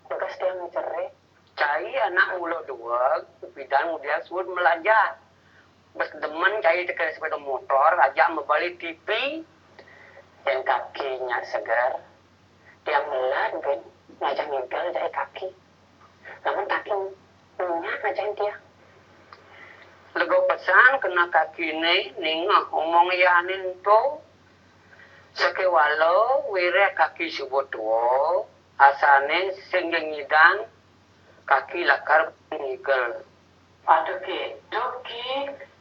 4 March 2012 at 8:18 pm I hear tones and a pronounced trill.